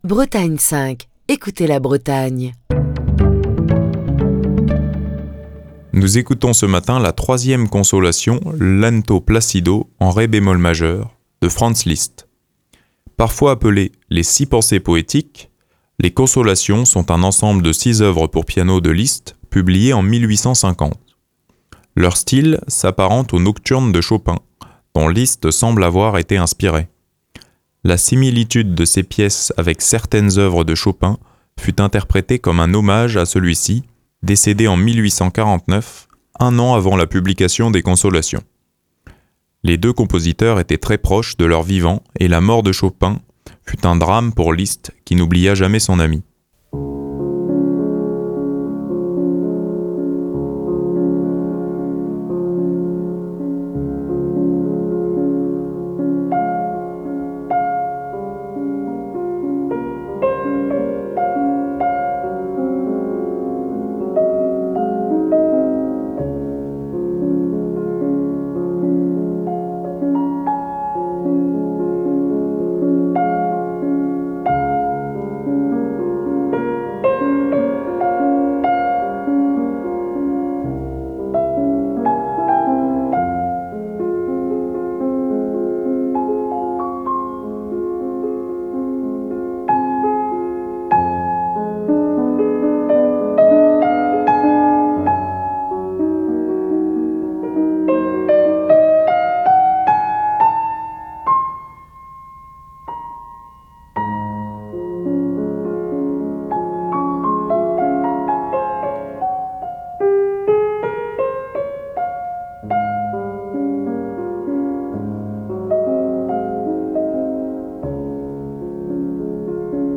3ème consolation, Lento placido en ré bémol majeur - Franz Liszt | Bretagne5
Fil d'Ariane Accueil Les podcasts 3ème consolation, Lento placido en ré bémol majeur - Franz Liszt 3ème consolation, Lento placido en ré bémol majeur - Franz Liszt Émission du 21 mars 2024. Parfois appelées, les Six pensées poétiques, Les Consolations sont un ensemble de six œuvres pour piano de Franz Liszt, publiées en 1850.